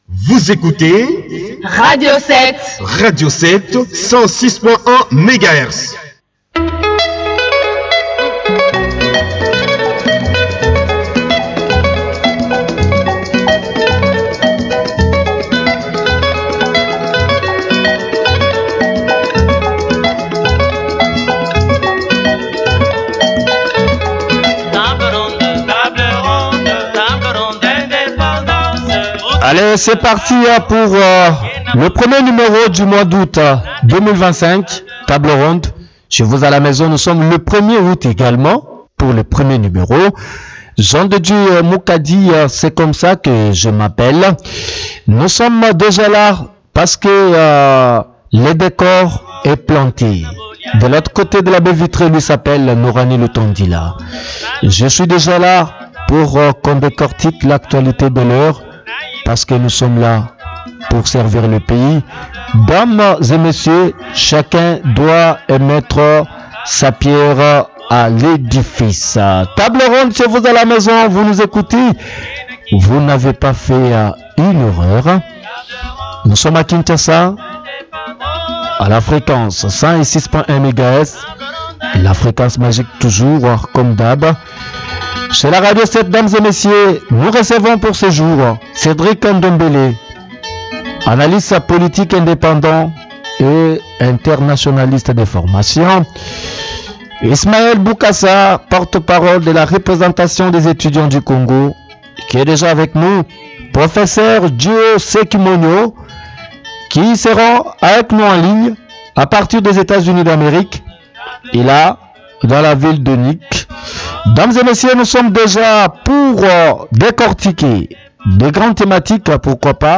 Table Ronde : Genecost – Ce que l’on vous dit… et surtout ce qu’on ne vous dit pas.